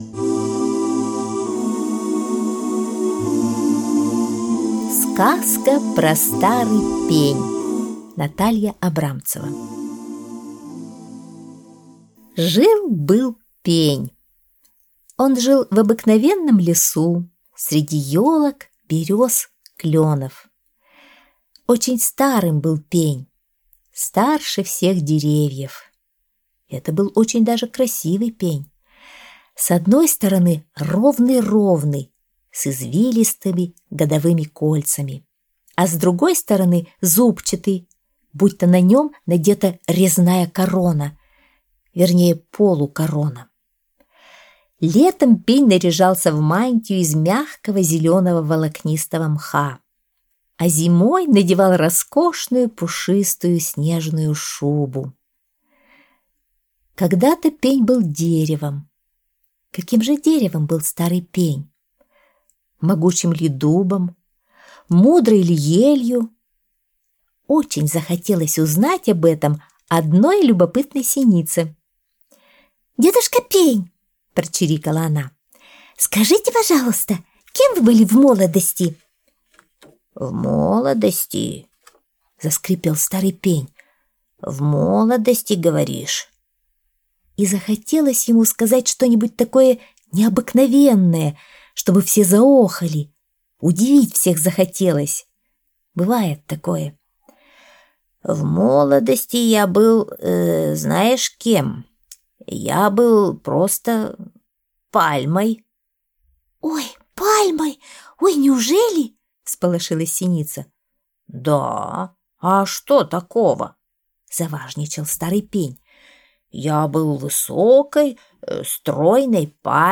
Сказка про старый пень (аудиоверсия)